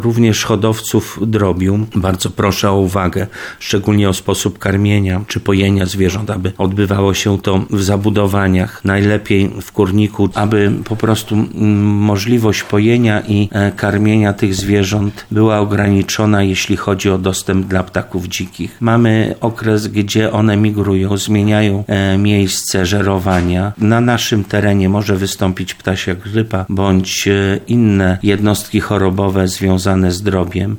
Powiatowy Lekarz Weterynarii w Tarnowie Paweł Wałaszek w rozmowie z RDN Małopolska tłumaczy jak zmniejszyć ryzyko zakażenia u ptaków hodowanych w prywatnych gospodarstwach.